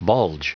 Prononciation du mot bulge en anglais (fichier audio)
Prononciation du mot : bulge